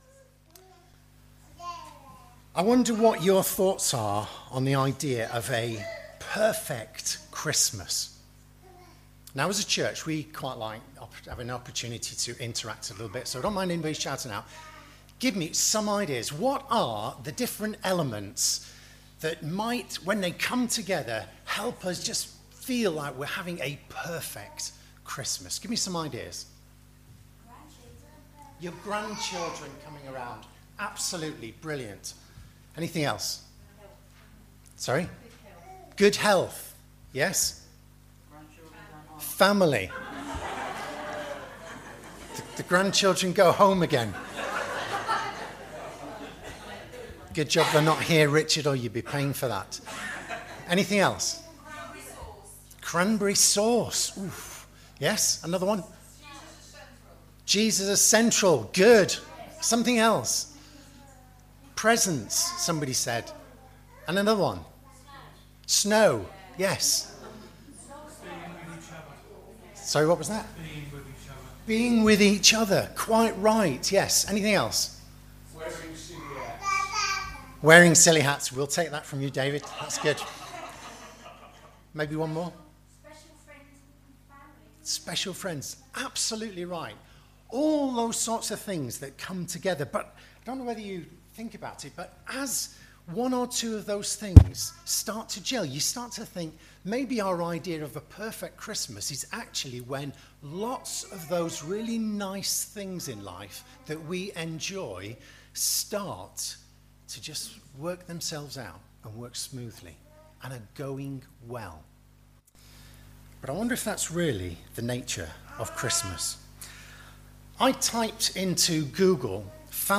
Advent 3 Carol service – The perfect Christmas